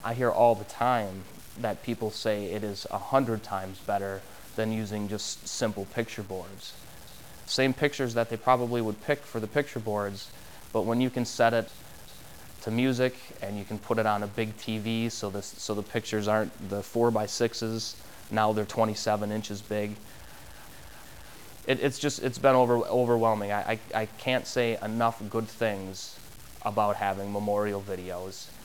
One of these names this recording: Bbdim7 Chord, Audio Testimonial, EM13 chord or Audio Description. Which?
Audio Testimonial